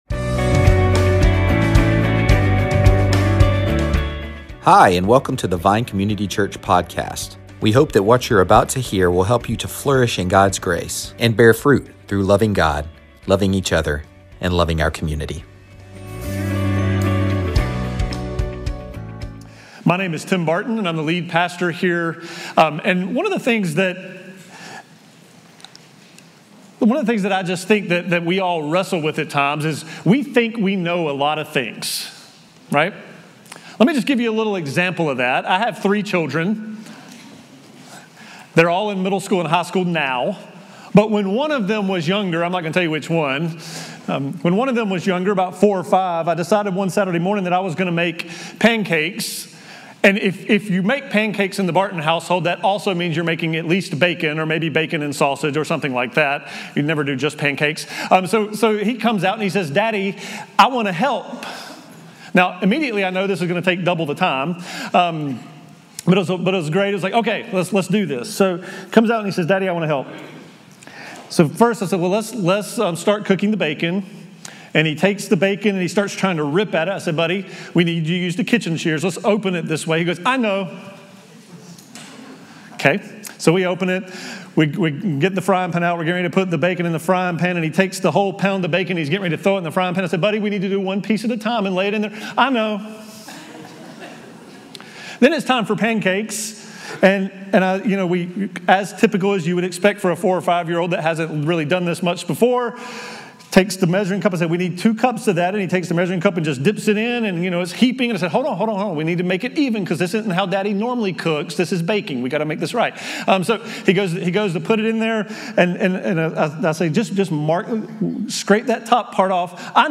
Sermons |